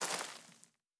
added stepping sounds
Undergrowth_Mono_03.wav